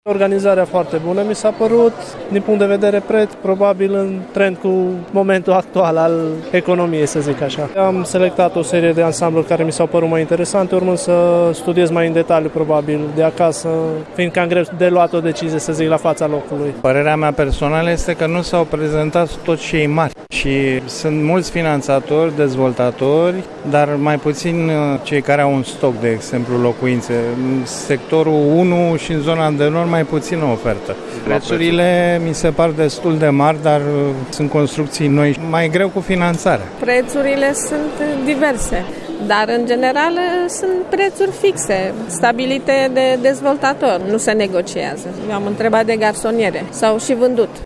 VOX-TARG.mp3